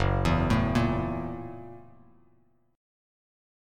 F#7#9 chord